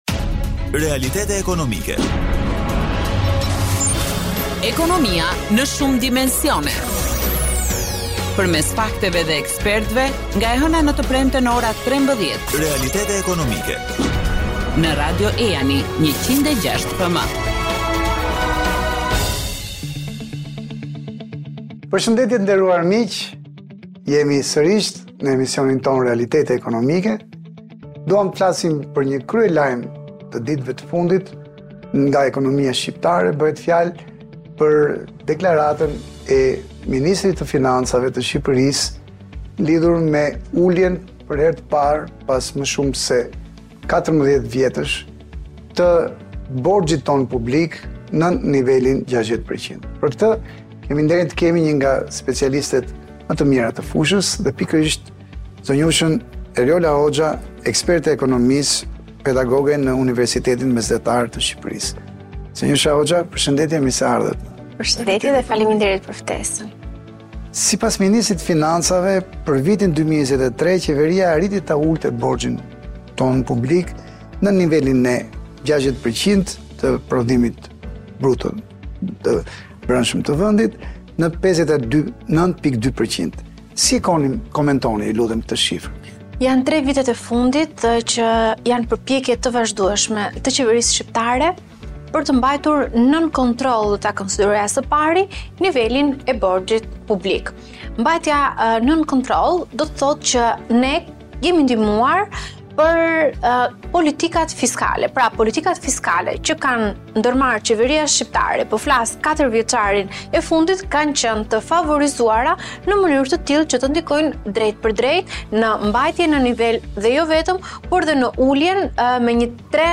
intervistë